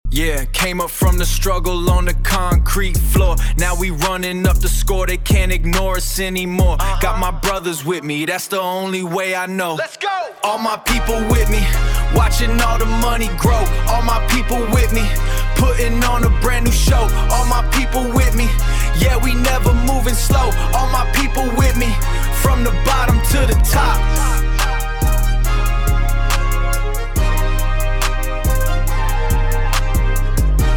Category:Rap